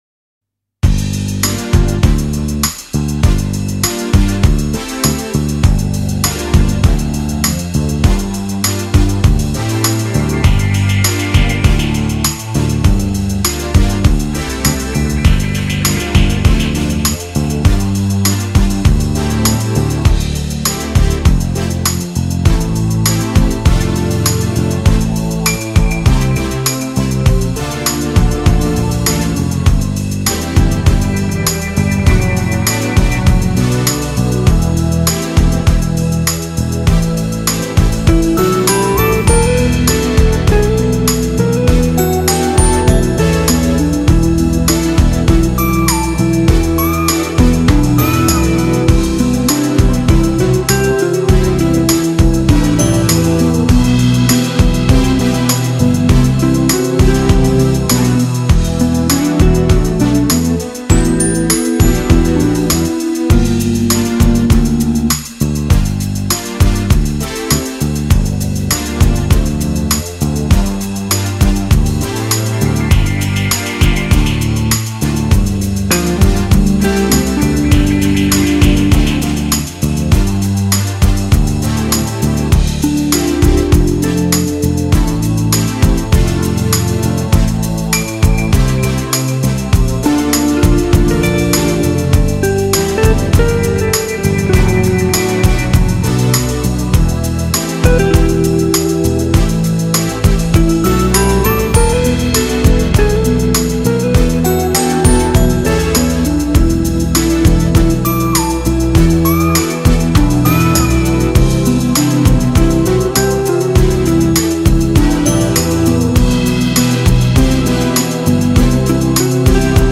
Largo [0-10] melancolie - ensemble instruments - - -